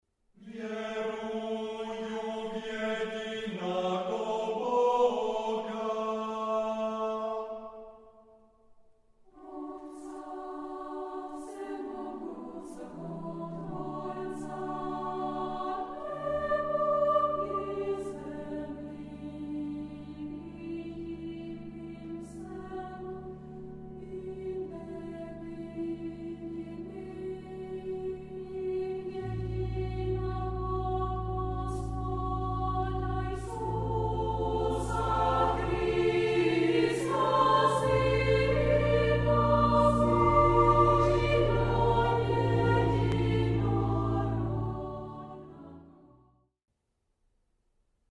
varhany